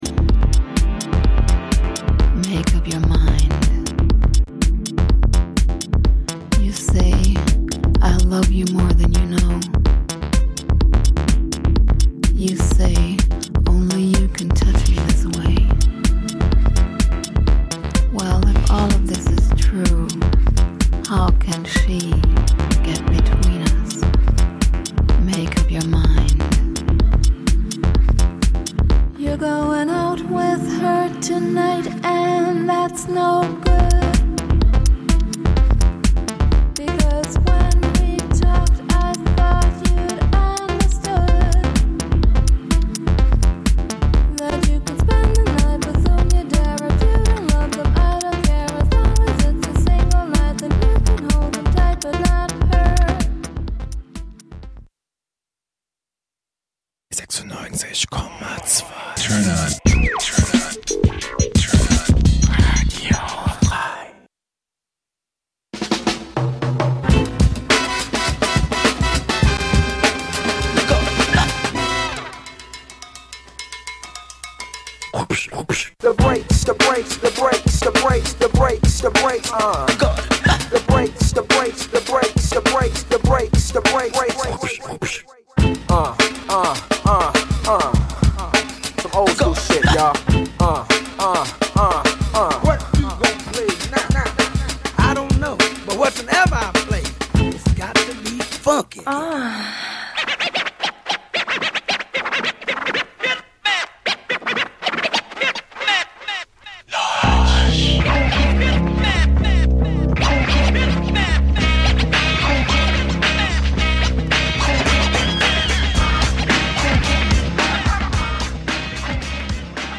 Hinter WHAT ABOUT BREAKS? verbergen sich unter dem alles �berspannenden Dach der Hip Hop Kultur neben den vorher genannten Stilrichtungen auch BLUES, LATIN, ELECTRO, REGGAE und POP mit jeder Menge Hintergrundinfos zur Musik und den K�nstlern.